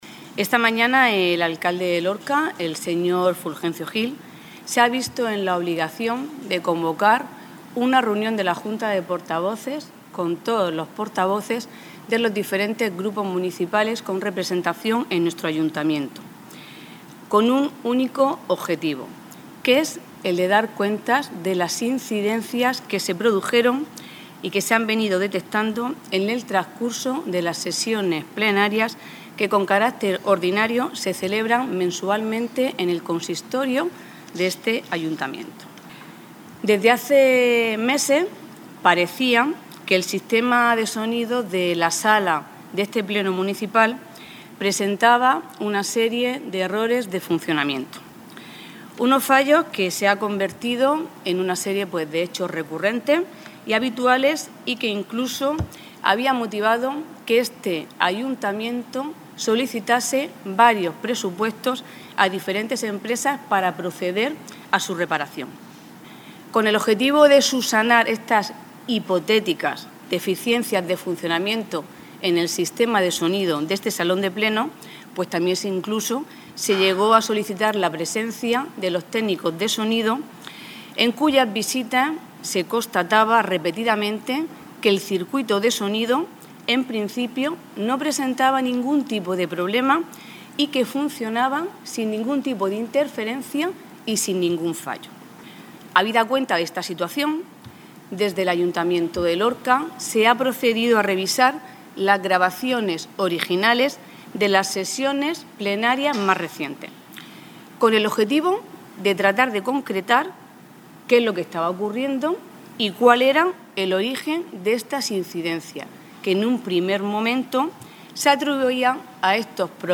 Declaraciones de Rosa Medina.